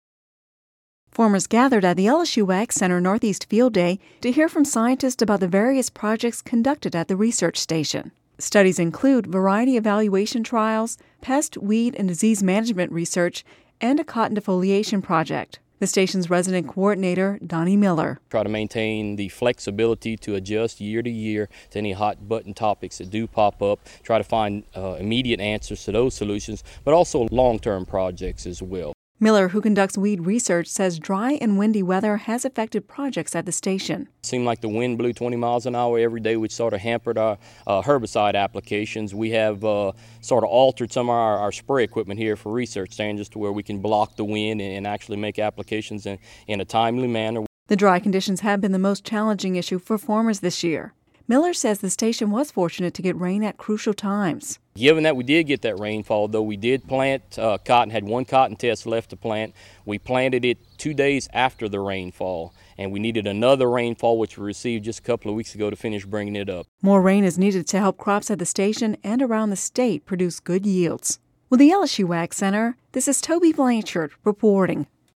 (Radio News 07/04/11) Farmers gathered at the LSU AgCenter's Northeast Research Station Field Day to hear from scientists about the various projects conductedin the area. Studies include variety evaluation trials; pest, weed and disease management research; and a cotton defoliation project.